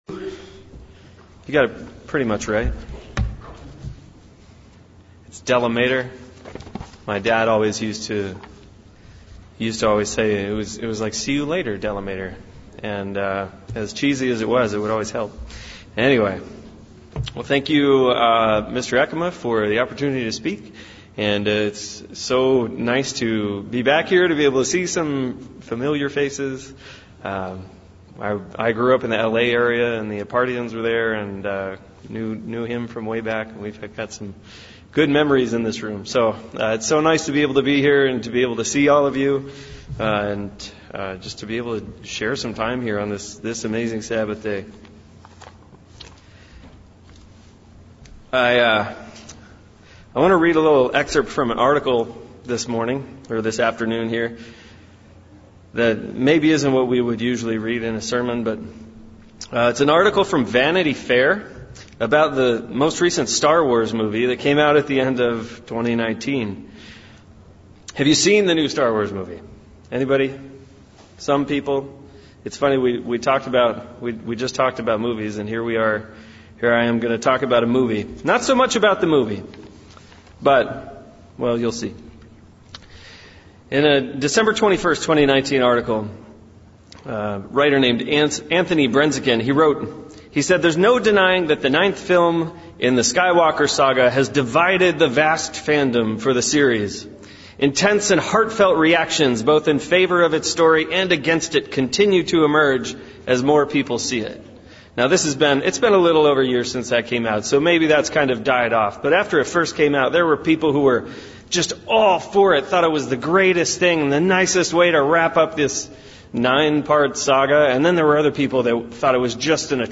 Guest speaker sermon looking at the subject of Polarization and how Christ dealt with it in 3 areas 1 - Rivalry 2 - Others outide 3 - Opppostion